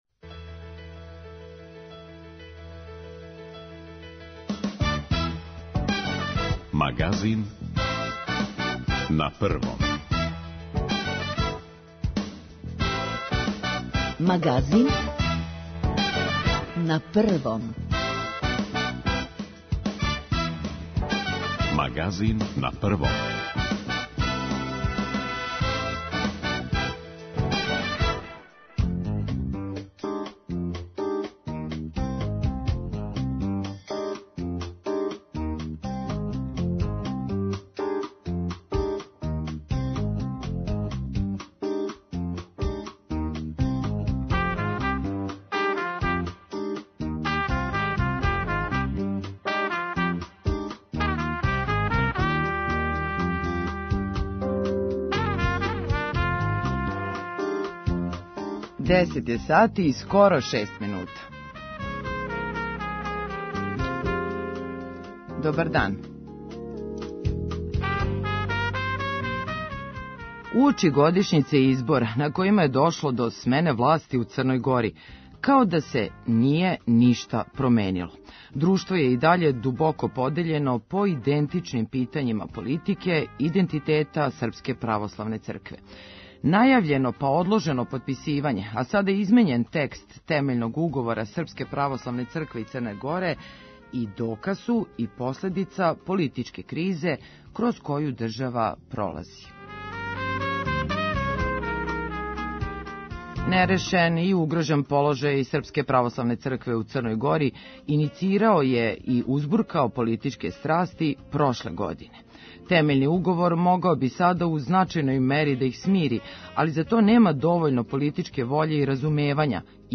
О чему се ради, шта се налази у последњој верзији коју је премијер Кривокапић послао патријарху Порфирију, и зашто је и даље на чекању Темељни уговор, то је централна тема данашњег Магазина. Наш гост је професор Богословског факултета у Београду и некадашњи министар вера Богољуб Шијаковић. преузми : 20.70 MB Магазин на Првом Autor: разни аутори Животне теме, атрактивни гости, добро расположење - анализа актуелних дешавања, вести из земље и света.